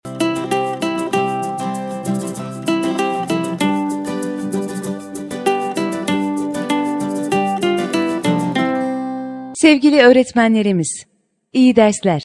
Okulumuz Öğretmen Ders Giriş Zili